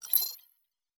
HiTech Click 5.wav